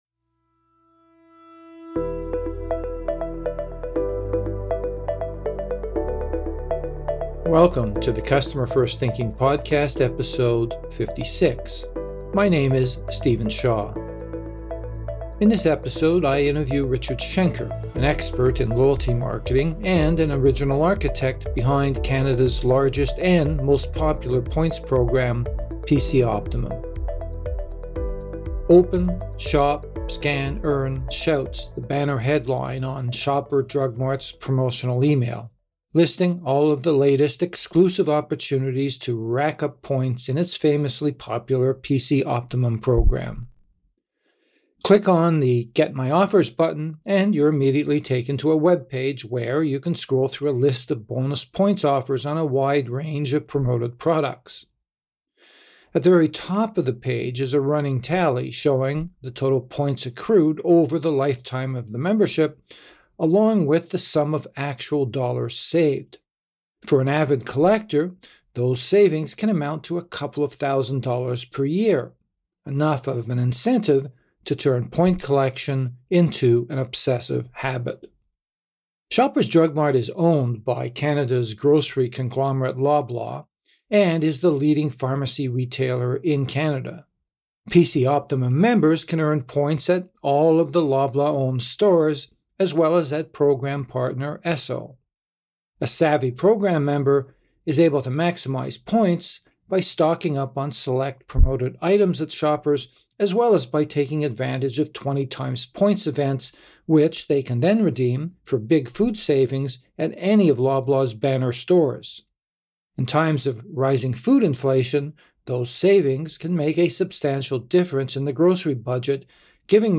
This interview has been edited for length and clarity.